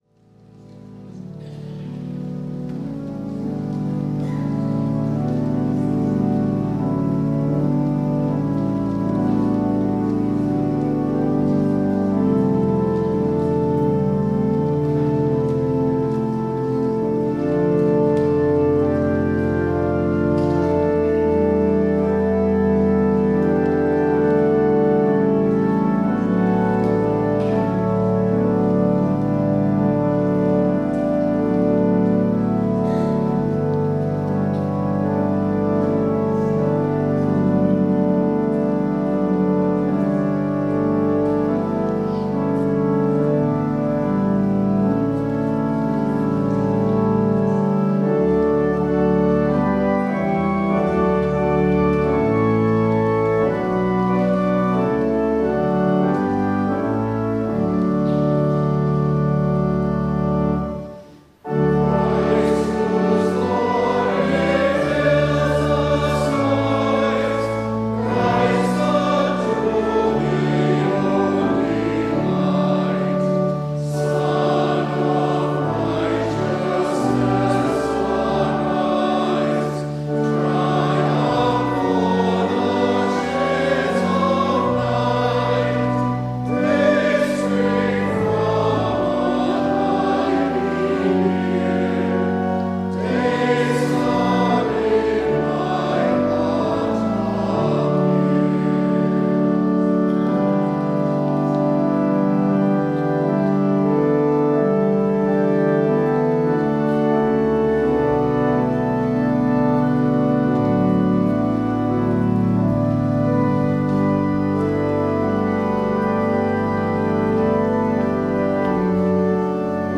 WORSHIP - 10:30 a.m. Seventh of Easter